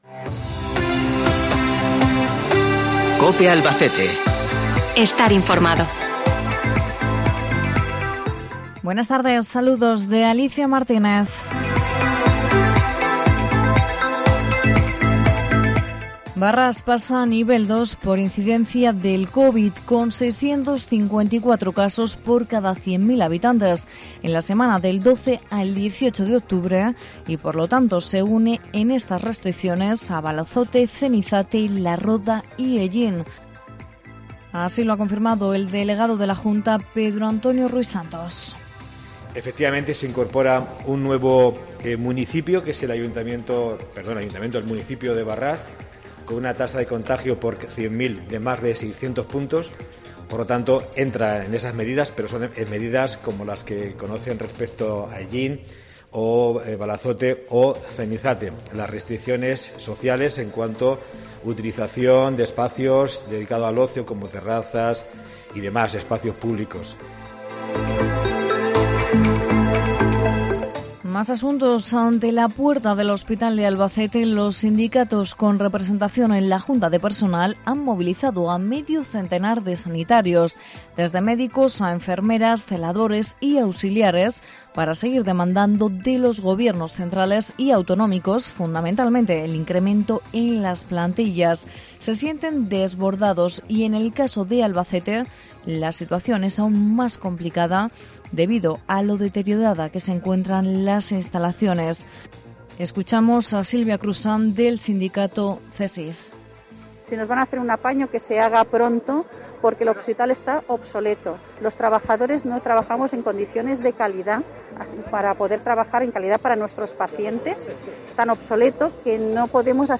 INFORMATIVO LOCAL 20 DE OCTUBRE